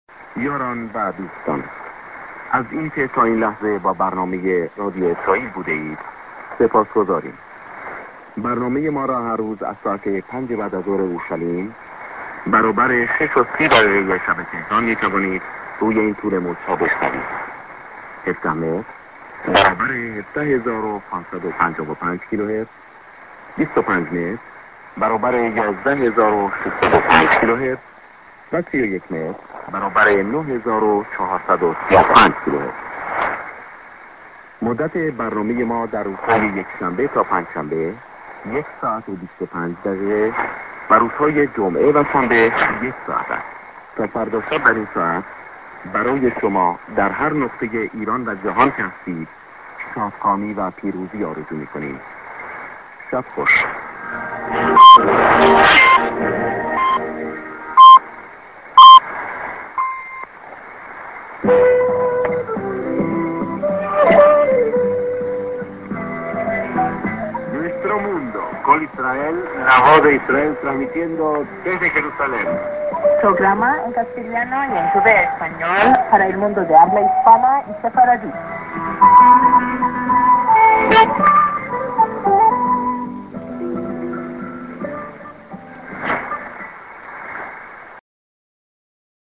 St. SKJ(man)->TS->SJ->ID(man+women)